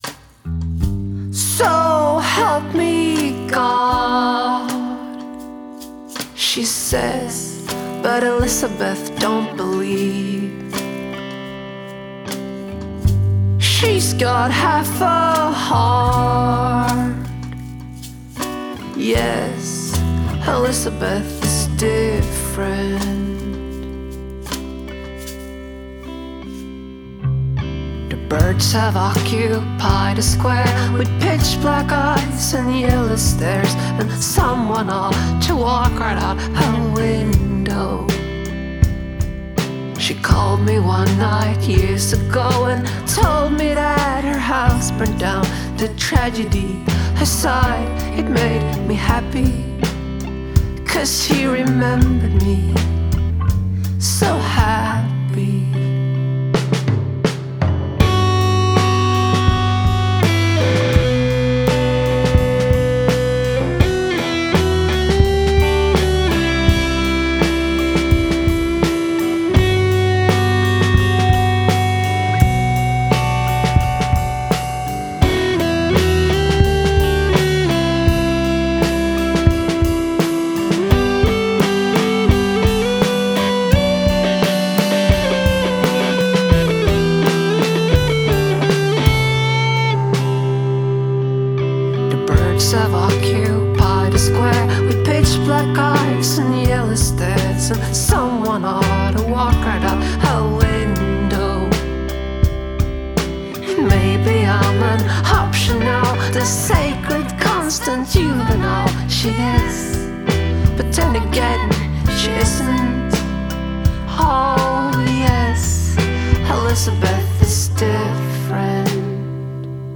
rock indé et à la dream pop